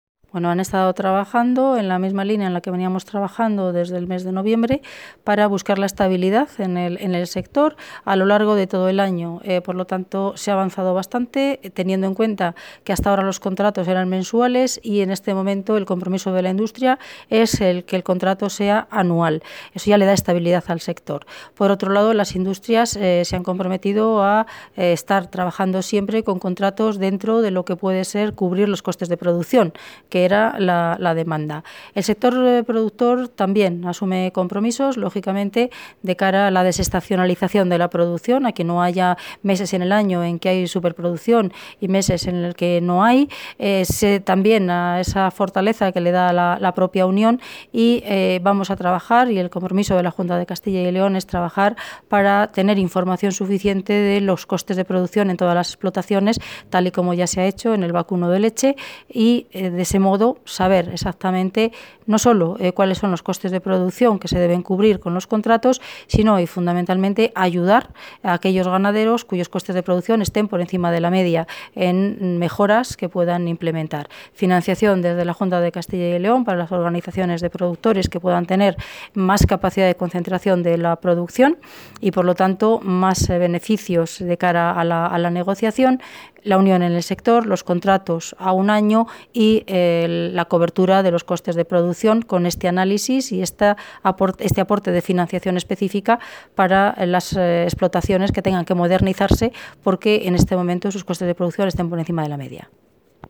La consejera de Agricultura y Ganadería, Milagros Marcos, ha valorado la reunión para el seguimiento de la contratación en el sector ovino y caprino de leche.